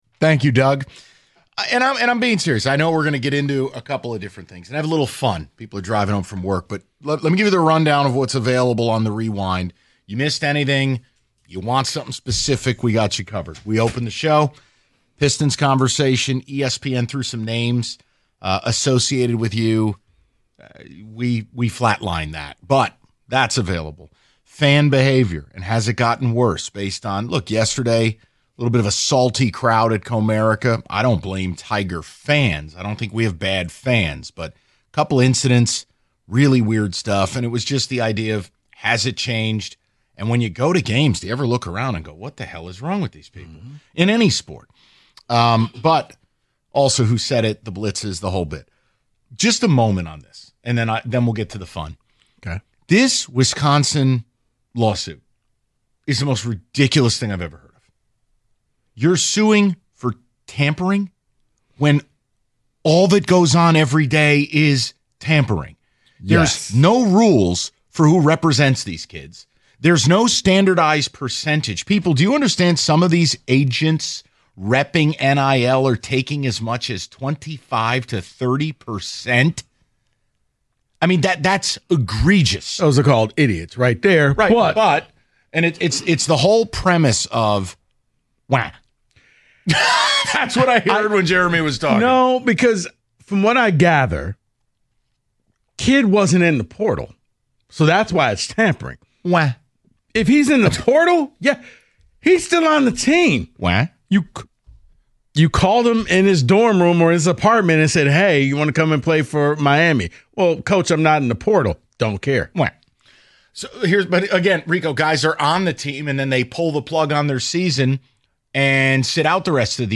They take a few more of your fan calls before wrapping up the show.